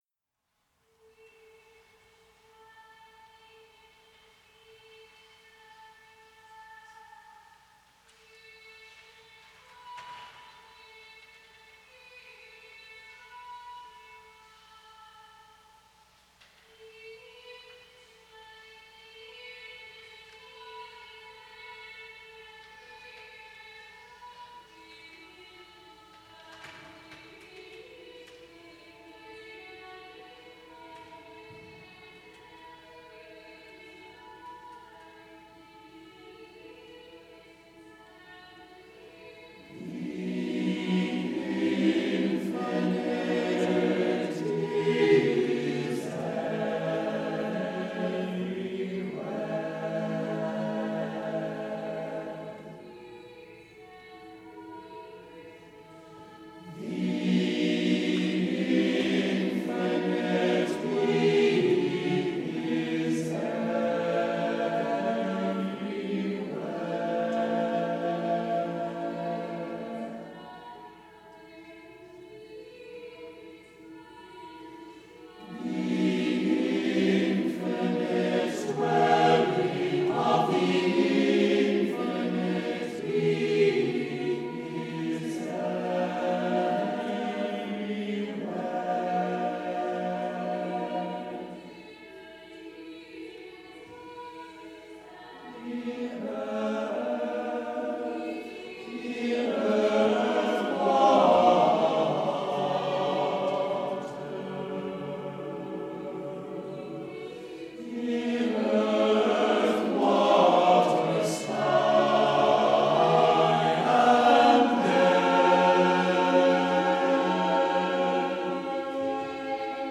SATB a cappella